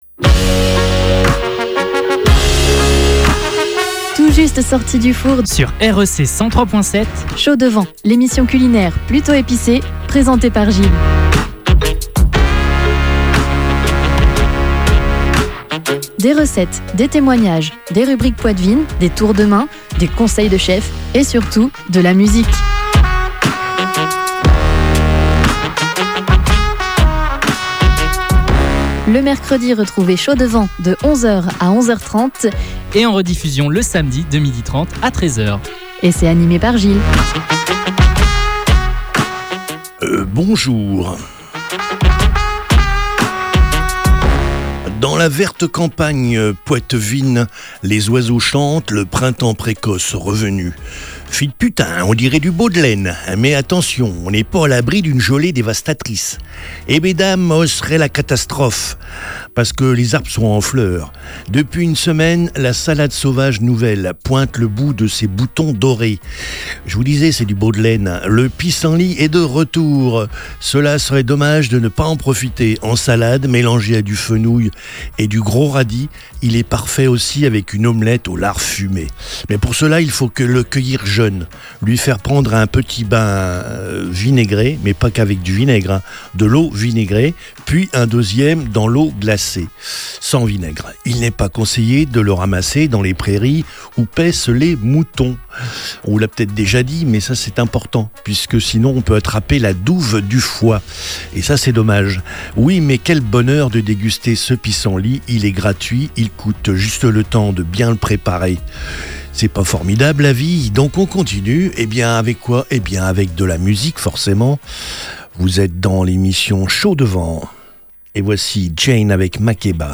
avec anecdotes, témoignages , rubriques , recettes avec des conseils de chef et forcément de la musique !